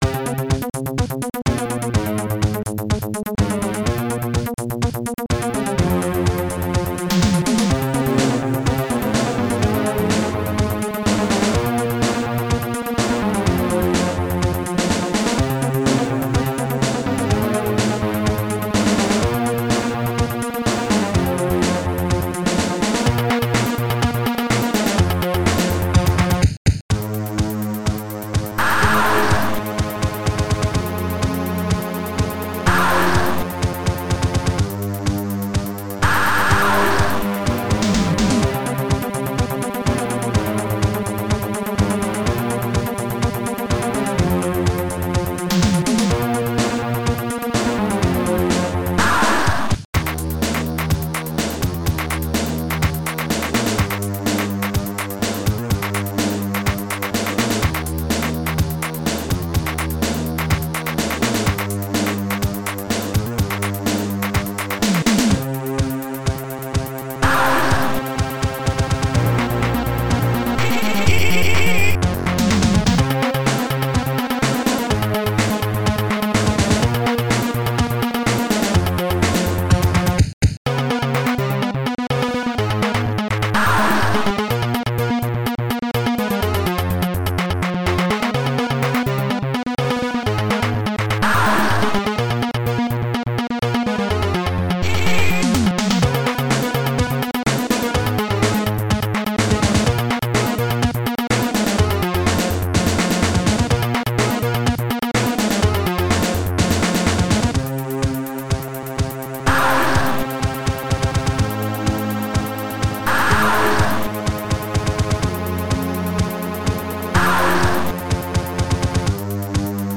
TCB Tracker Module